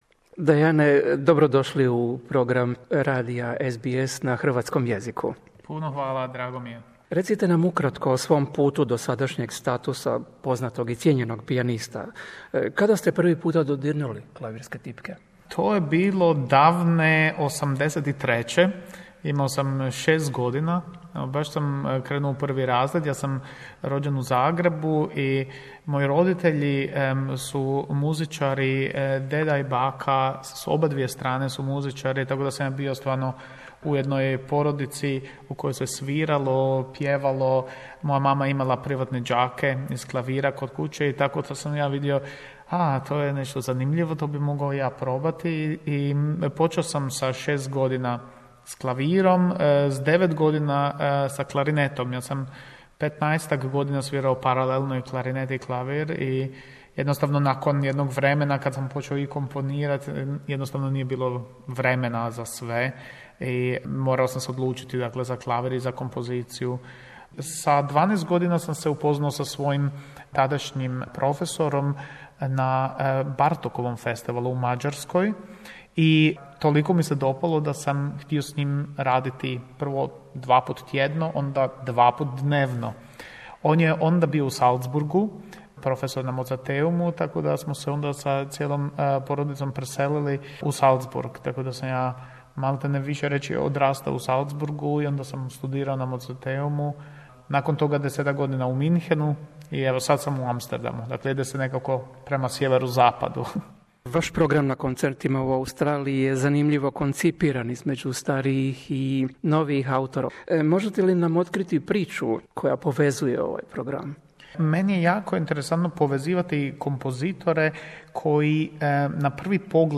Interview with Dejan Lazić, a prominent Croatian born pianist performing within the Melbourne Recital Centre series Great Performers 2016. He talks about his music training and beginnings of his musical career as a pianist and a composer. He also talks about the narrative of his Melbourne recital and future plans.